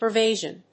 pur・vey・ance /pɚ(ː)véɪənspə(ː)‐/
音節pur･vey･ance発音記号・読み方pəːrvéɪ(ə)ns